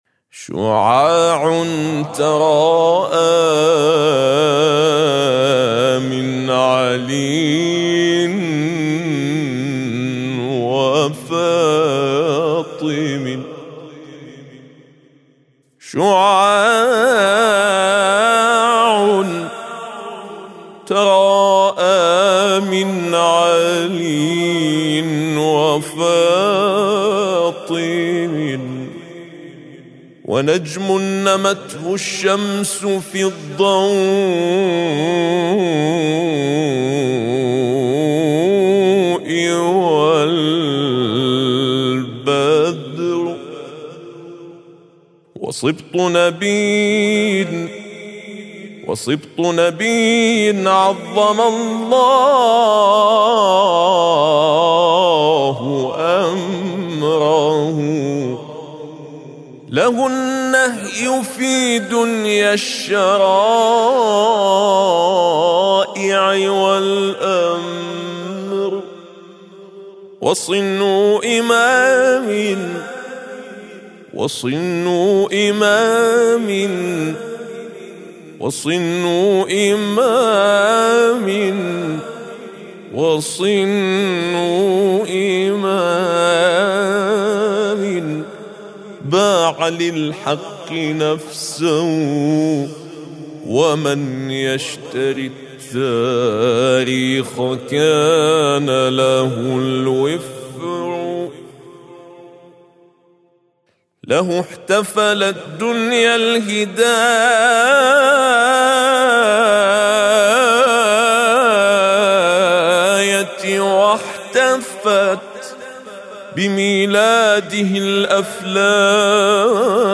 شعاع تراءا من علي وفاطم - ميلاد الإمام الحسن المجتبى (ع) - بصوت القارئ